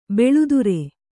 ♪ beḷudure